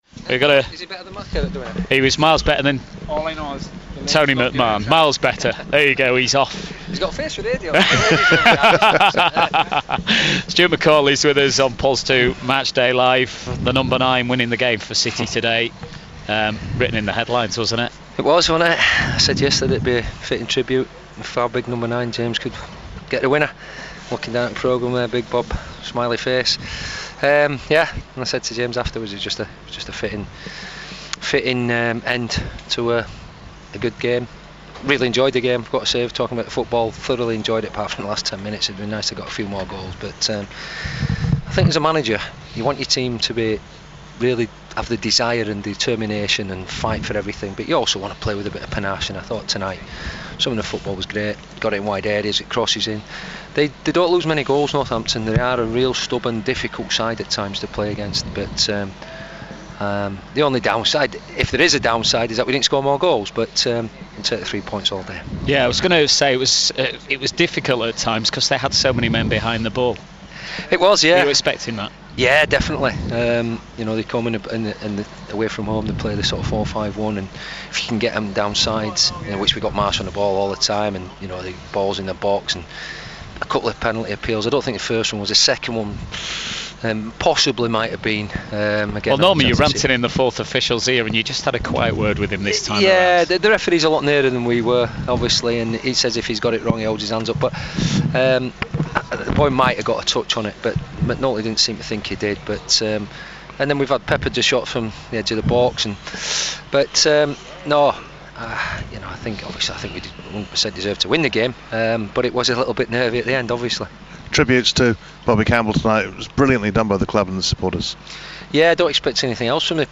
Stuart McCall post match interview. City 1-0 Northampton